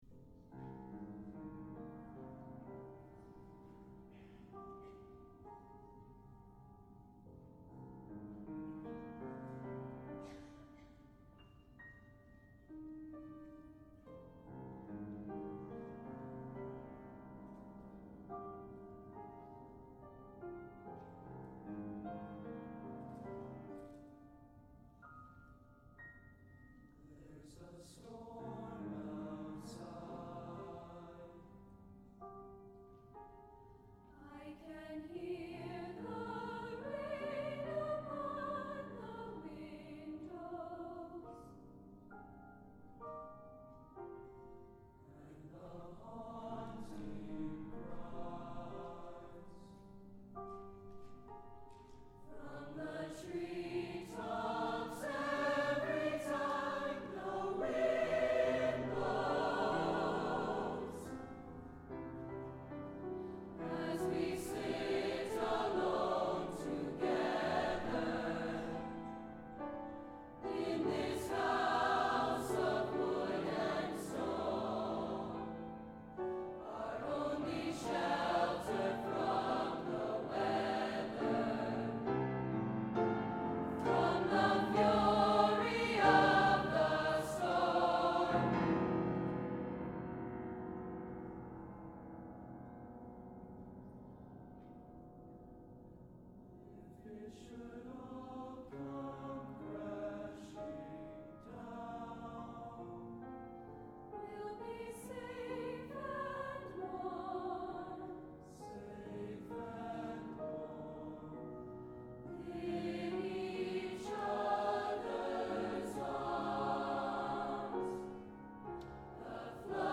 Arapahoe High School Concert Choir